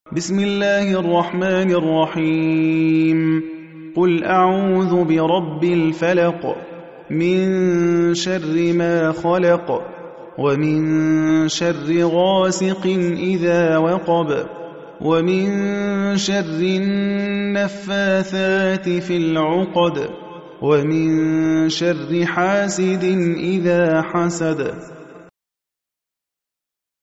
حفص عن عاصم